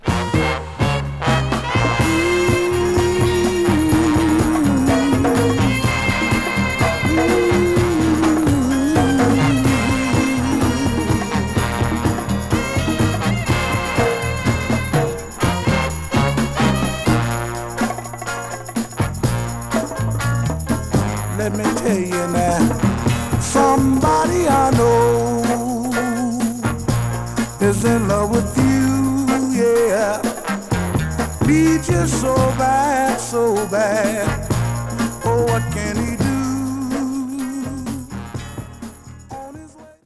Genre: Soul, Soul/Funk